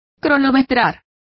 Complete with pronunciation of the translation of clocked.